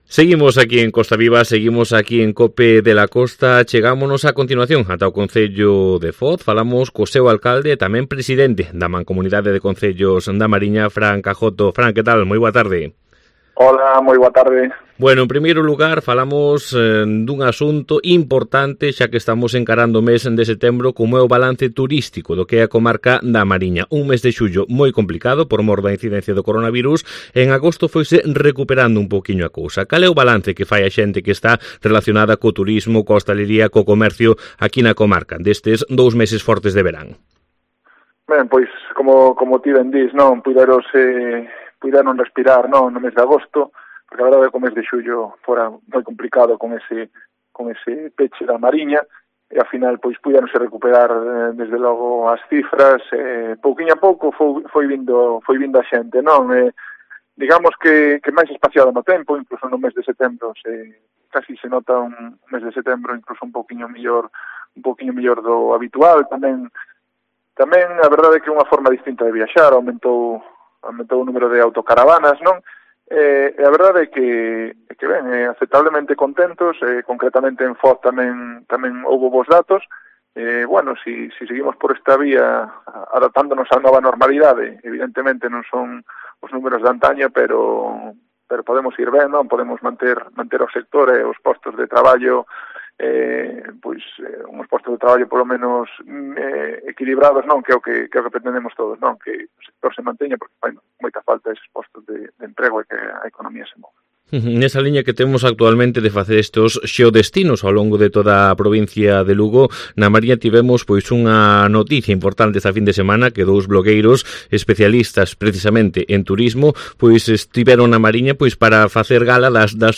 AUDIO: El presidente de la entidad, Fran Cajoto, habló en COPE de la Costa de la nueva página web y también sobre una visita efectuada por...